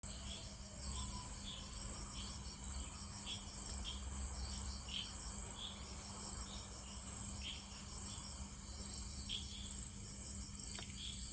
Scientific Name: Pseudophilautus Wynaadensis Common Name: Wynaad Brown Eared Shrub Frog